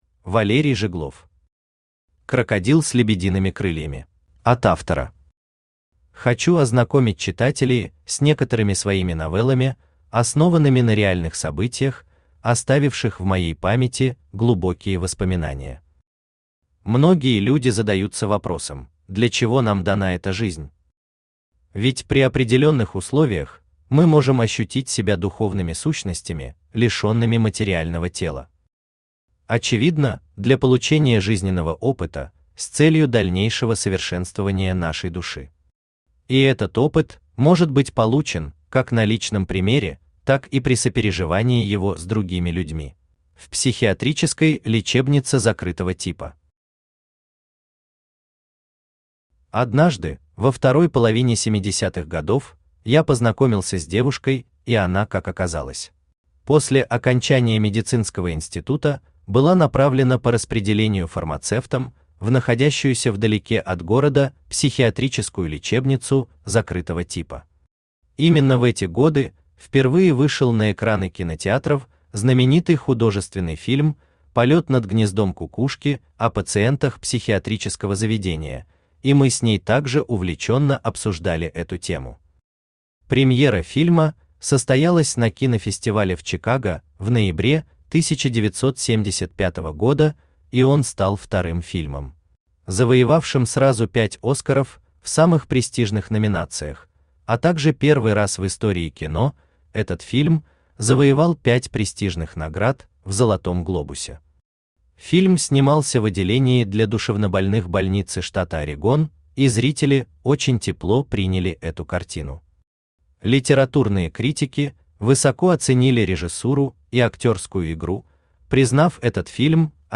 Aудиокнига Крокодил с лебедиными крыльями Автор Валерий Жиглов Читает аудиокнигу Авточтец ЛитРес.